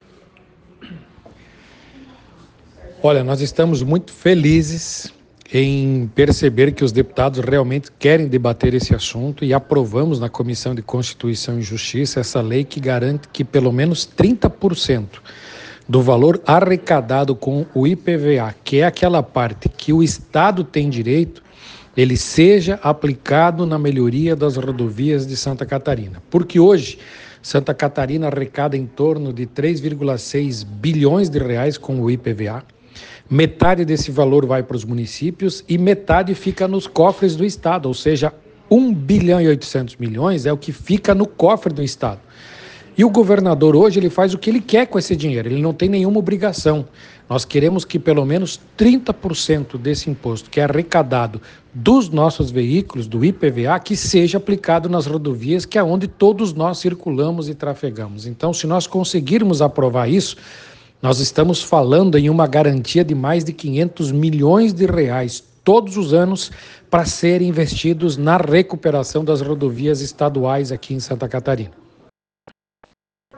Sonora_Fabiano_da_Luz.ogg